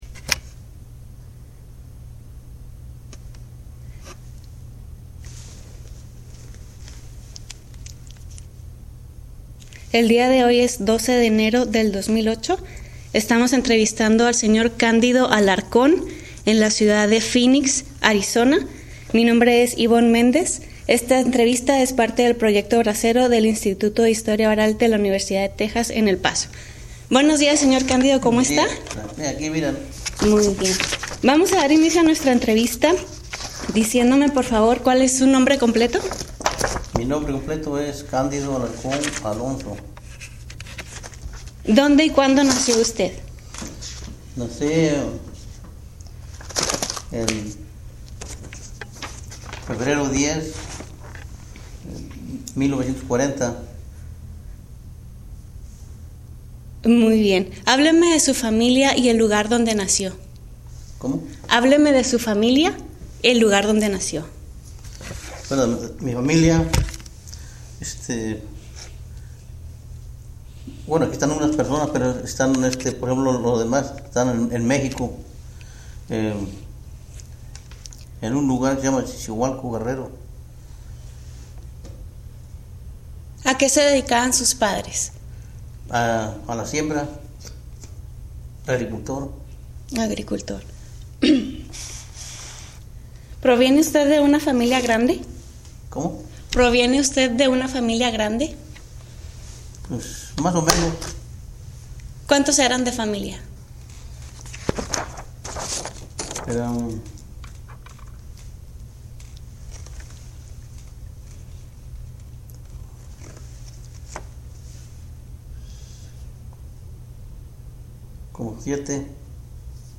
Location Phoenix, Arizona